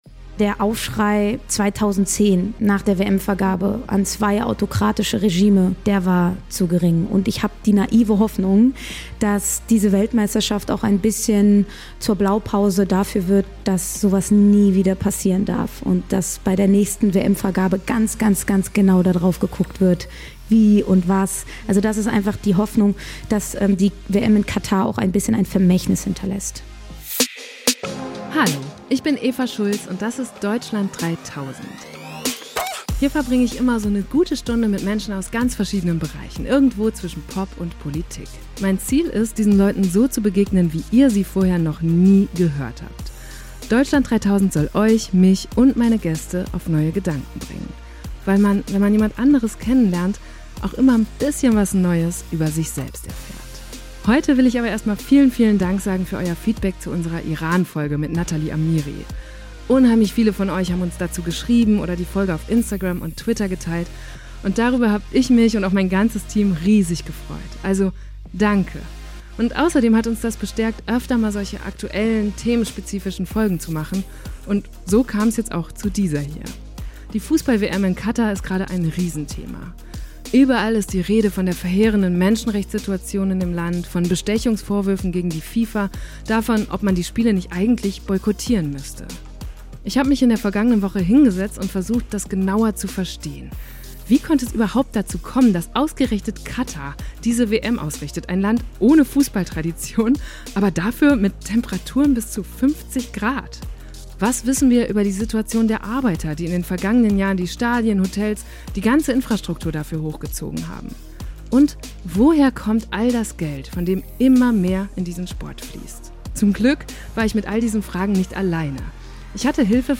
Ich hatte Hilfe von der Sportjournalistin